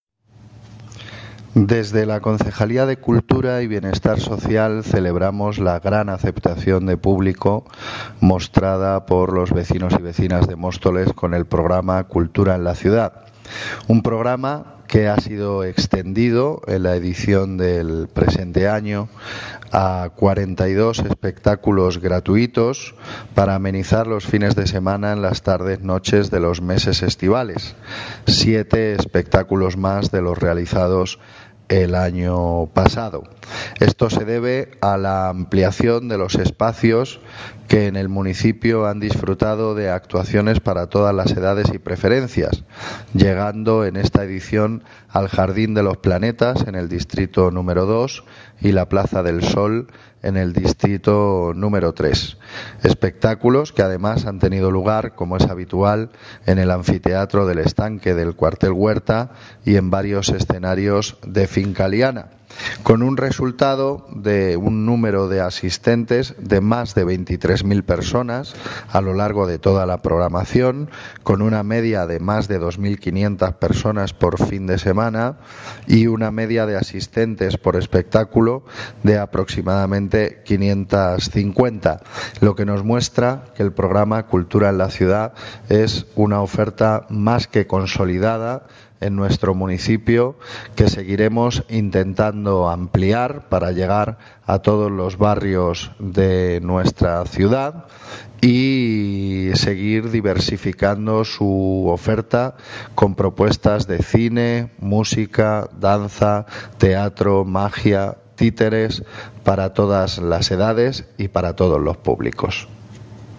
Gabriel Ortega (Concejal de Cultura) sobre cultura en la ciudad
Gabriel Ortega (Concejal de Cultura) sobre cultura en la ciudad.mp3